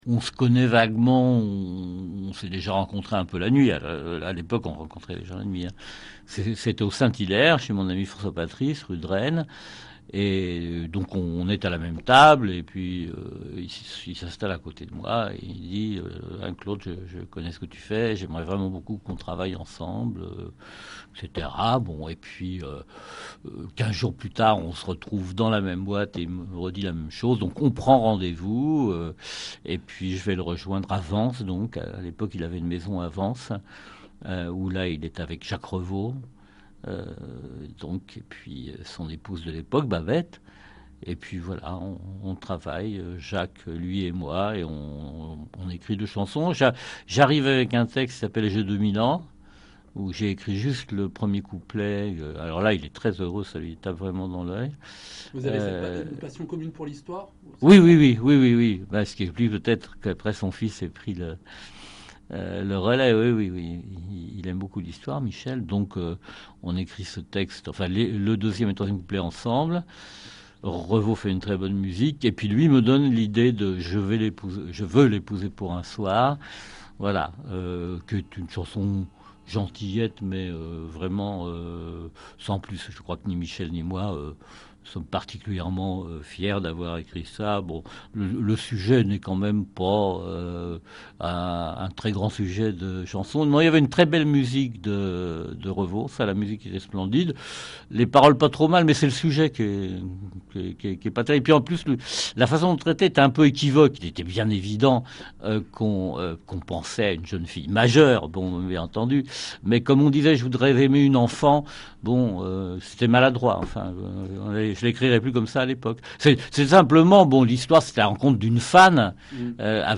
ILS PARLENT DE SARDOU Interview